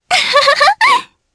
Selene-Vox_Happy3_jp.wav